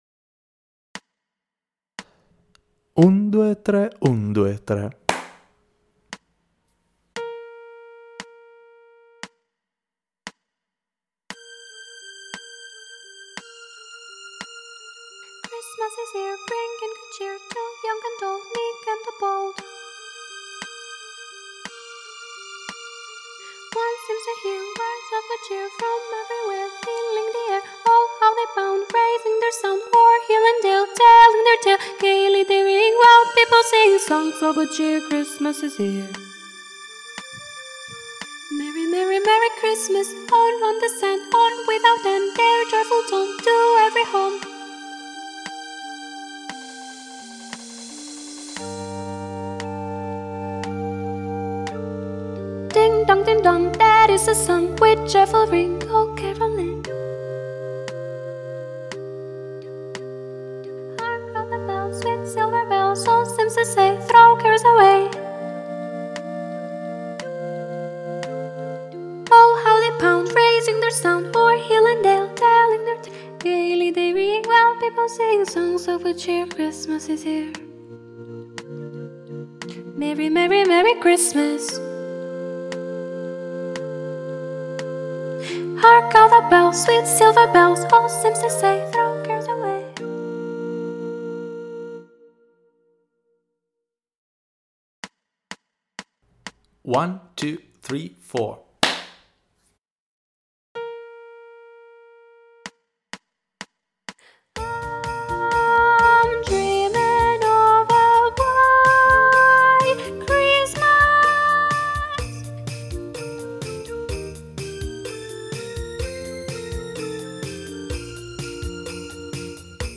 Mp3 traccia guida coro femminile - soprano